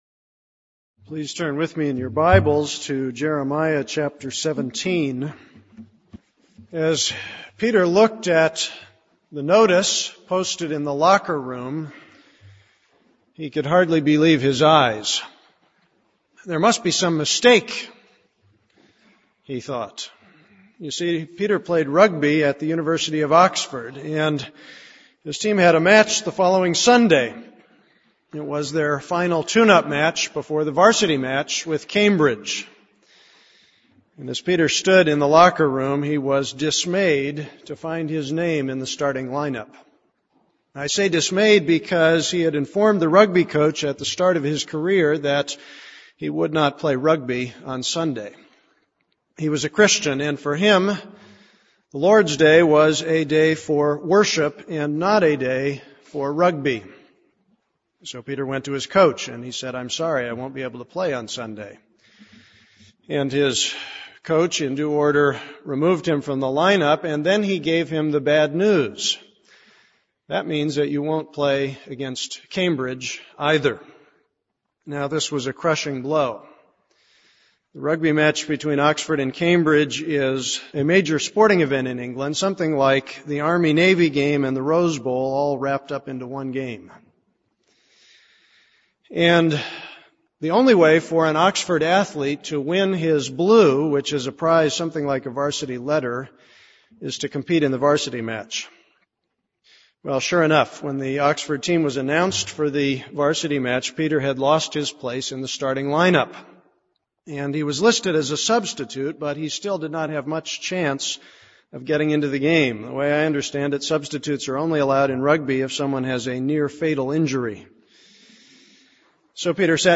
This is a sermon on Jeremiah 17:19-27.